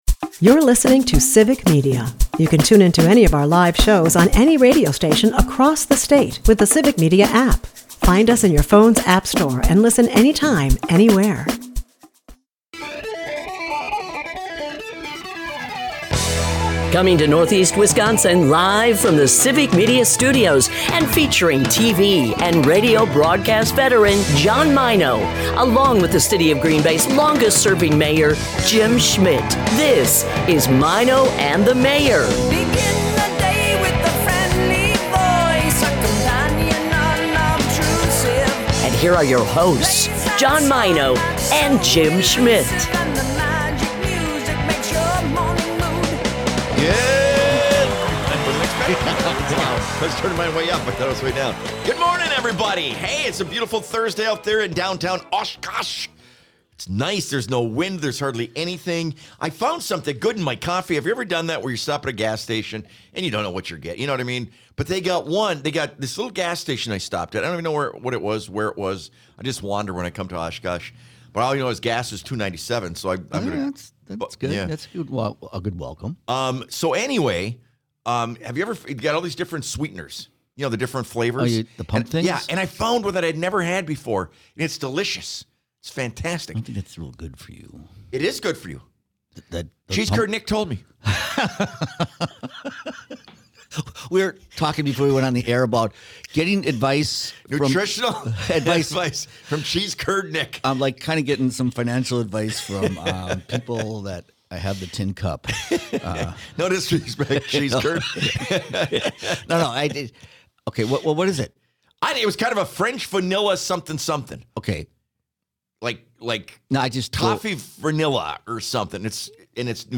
It's Trucker Thursday from our Oshkosh studios!